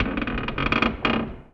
metal_low_creak_squeak_05.wav